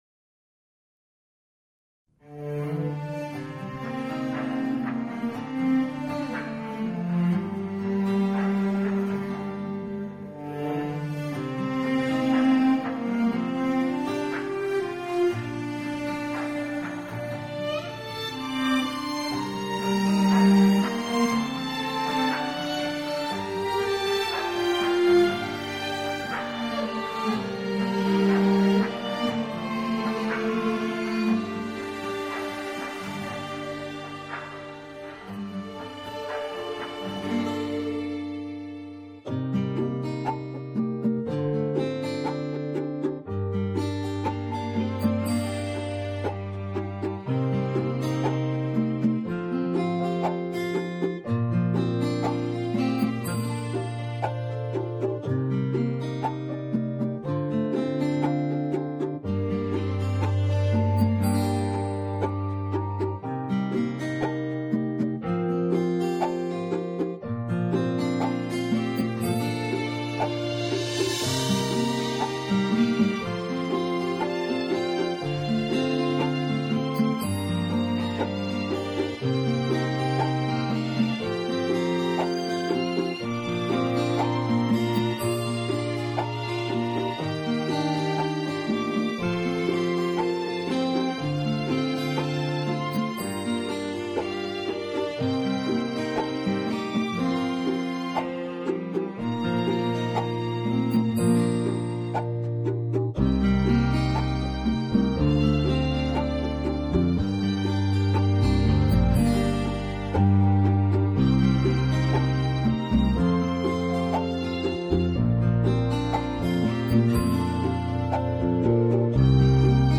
调式 : 降B-C 曲类